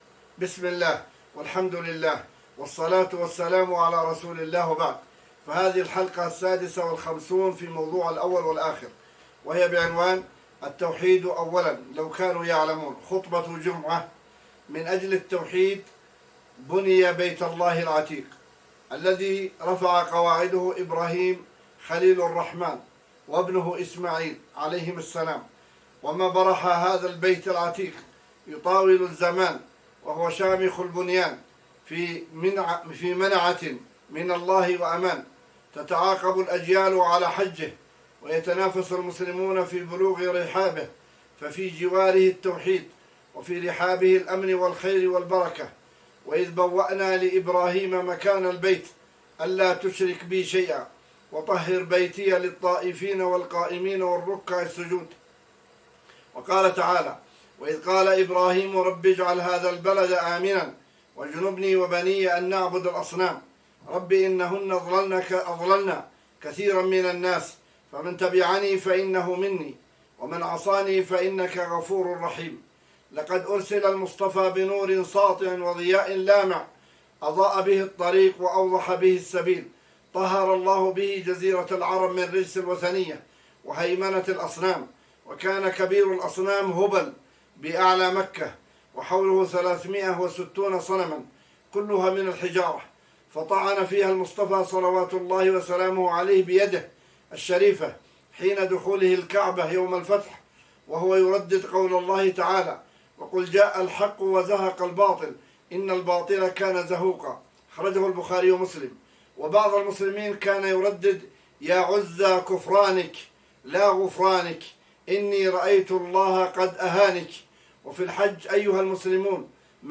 * التوحيد أولا (لو كانوا يعلمون) خطبة جمعة :